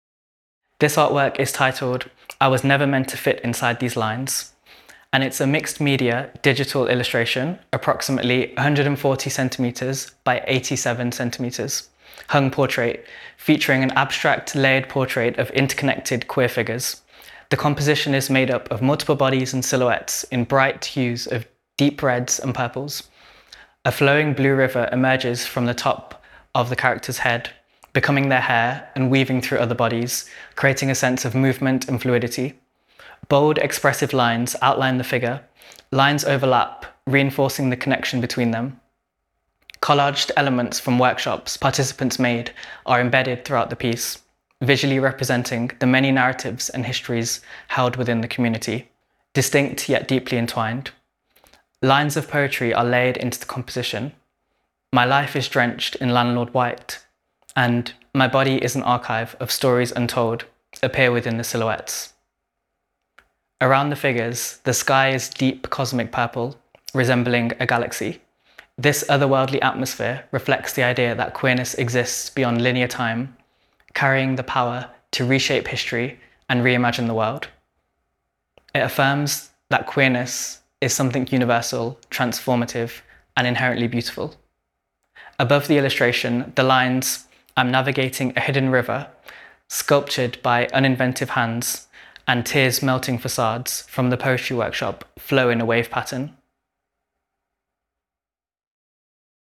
We Have Always Been Here – Audio Descriptions of Exhibition Artwork
Artwork descriptions – audio